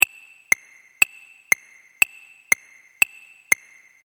| tictac 01 effect |